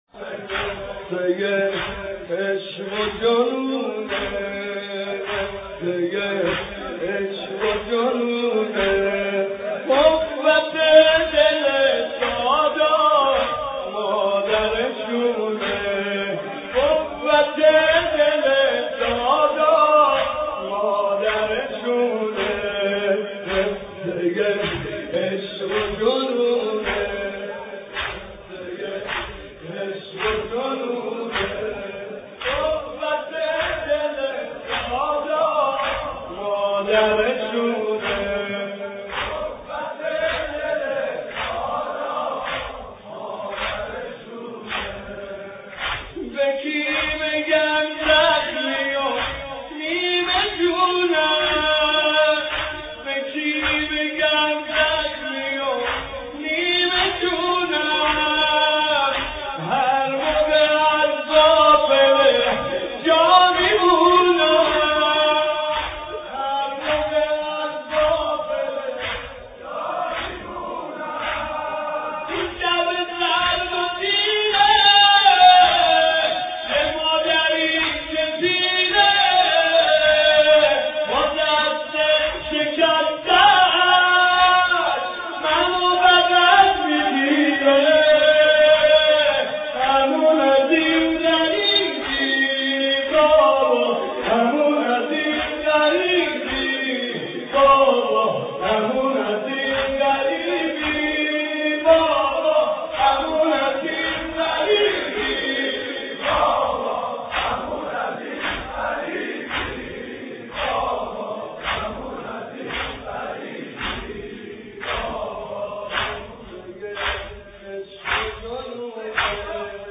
نوحه مداح اهل بیت استاد